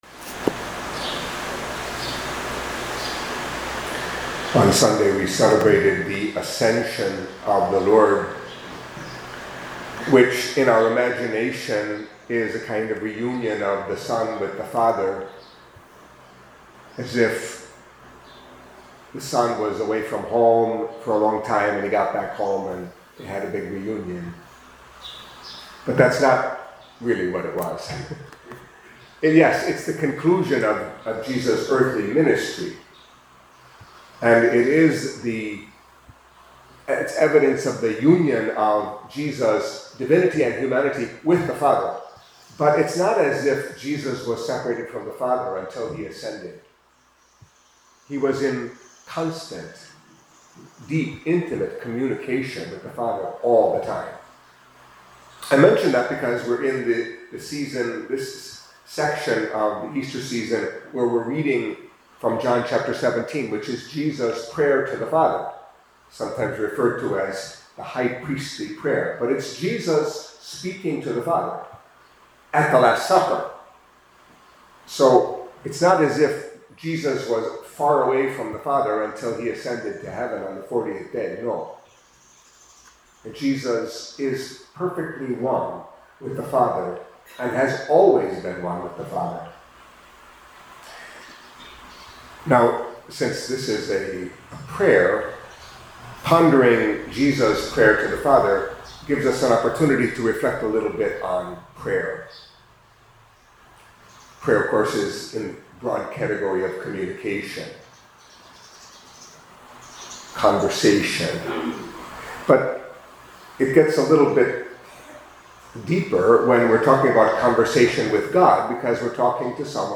Catholic Mass homily for Wednesday of the Seventh Week of Easter